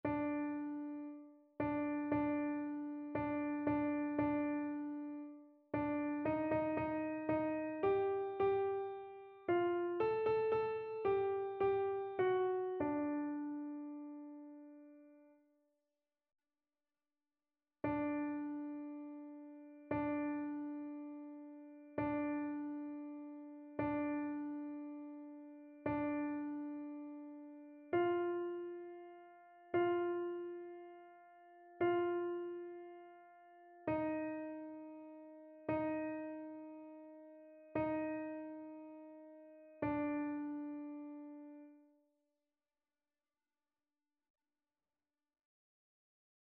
annee-b-temps-de-l-avent-1er-dimanche-psaume-79-alto.mp3